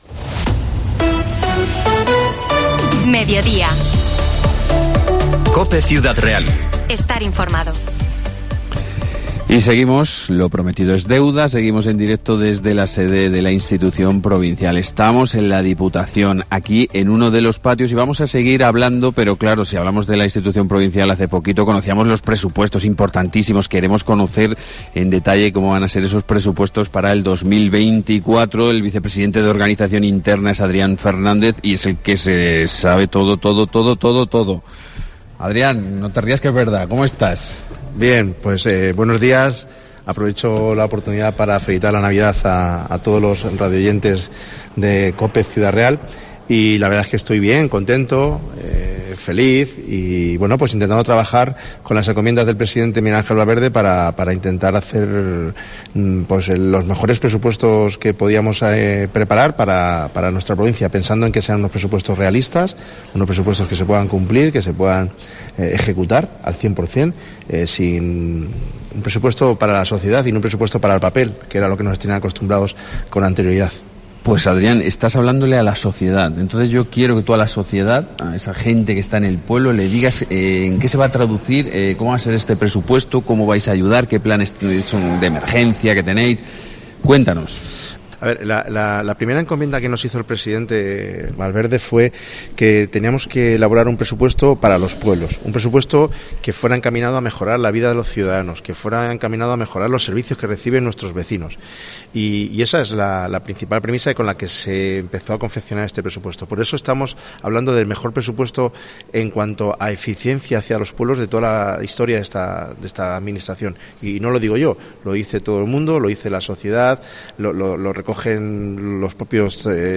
Herrera en COPE Ciudad Real desde el Palacio Provincial, sede de Diputación de Ciudad Real - 12,50
Así lo ha asegurado hoy, en el programa especial que COPE Ciudad Real ha emitido en directo desde el patio del Palacio Provincial el presidente de la institución, Miguel Ángel Valverde.